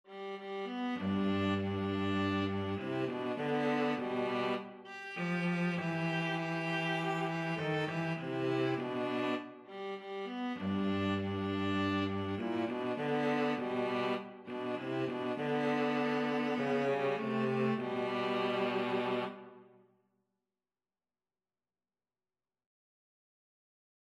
Christian
4/4 (View more 4/4 Music)
Viola-Cello Duet  (View more Easy Viola-Cello Duet Music)